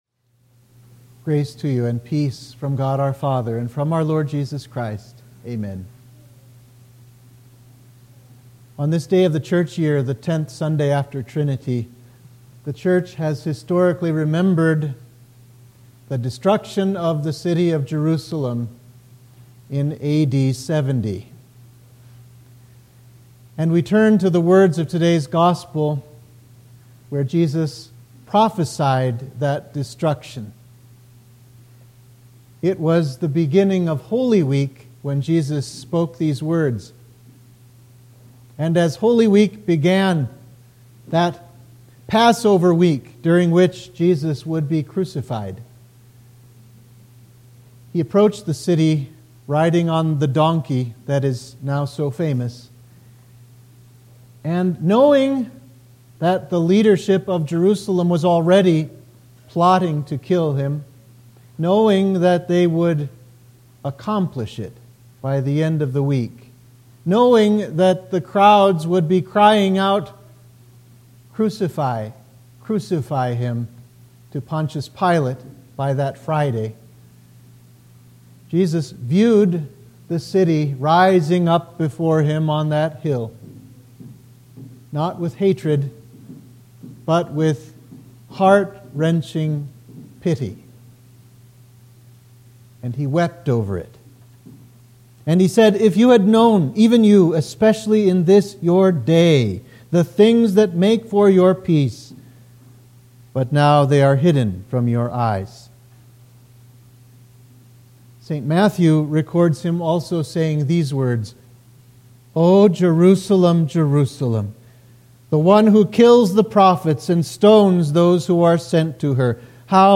Sermon for Trinity 10